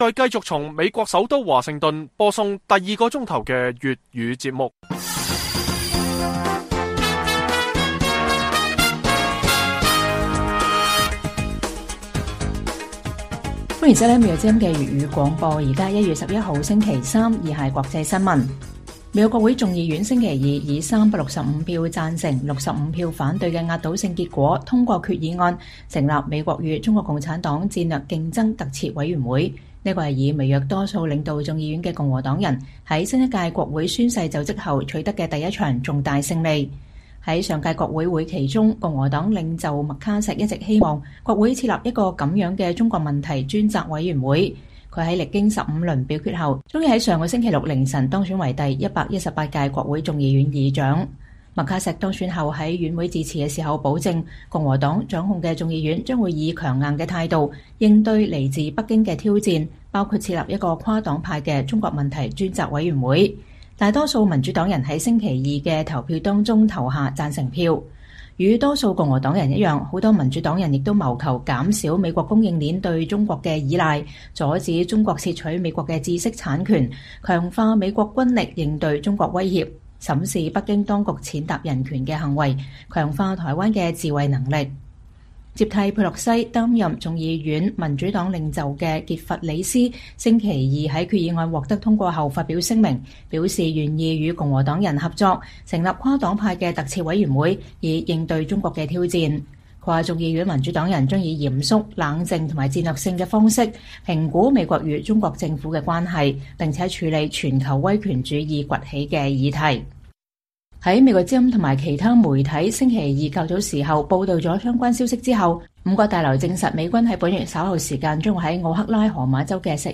粵語新聞 晚上10-11點: 楊紫瓊獲得美國金球獎音樂或喜劇類影后